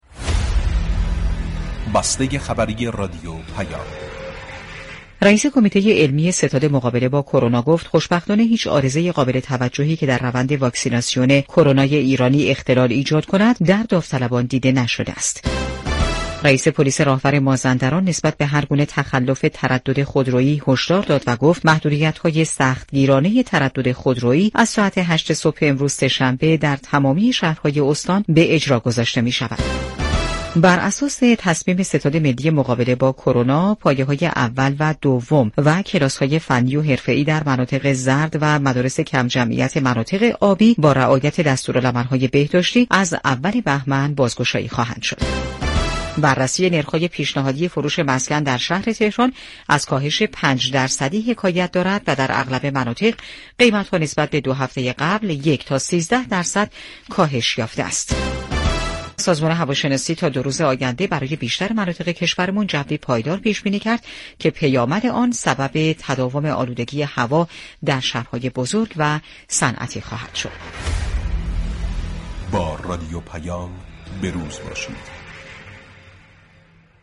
بسته خبری صوتی رادیو پیام